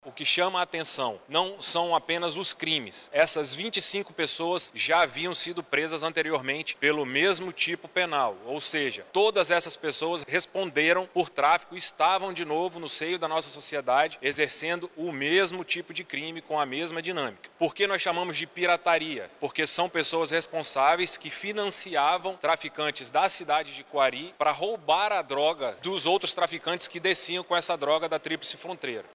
De acordo com o delegado-geral da Polícia Civil do Amazonas – PC/AM, Bruno Fraga, as pessoas presas são reincidentes nessas práticas criminosas.